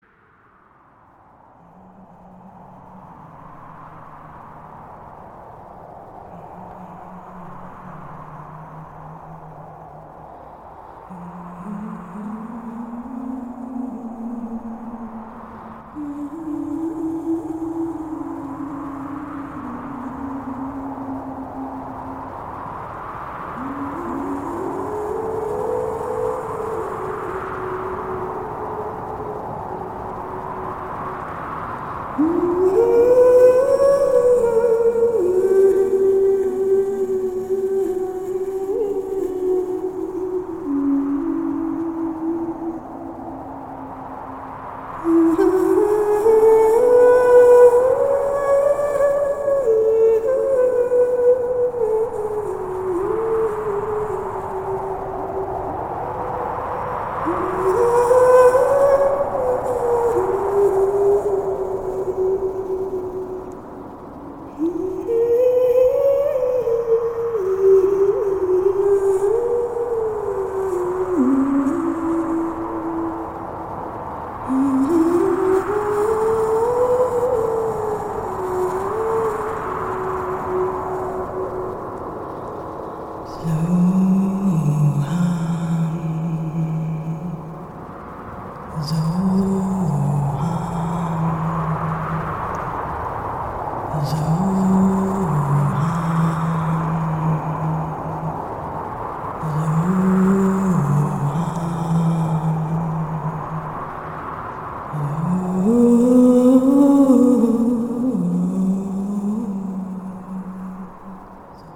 Musique de ralaxation - Yoga